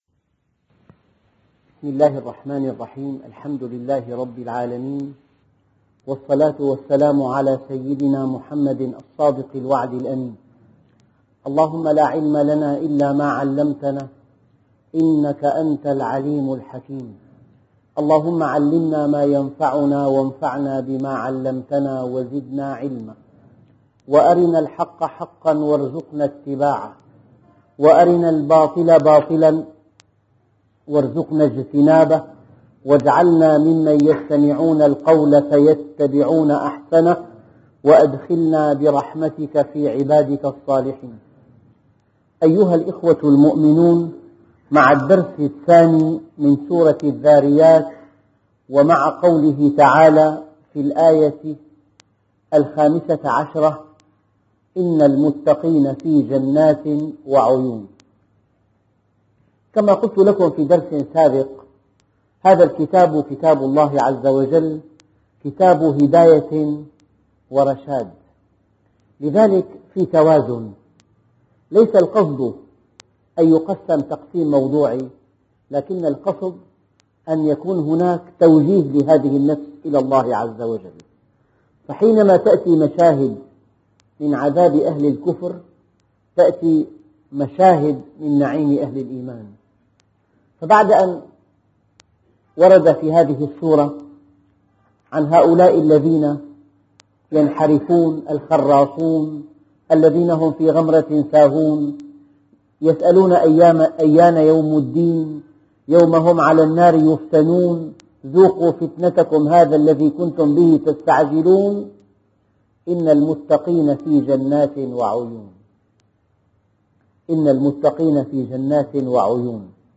أرشيف الإسلام - ~ أرشيف صوتي لدروس وخطب ومحاضرات د. محمد راتب النابلسي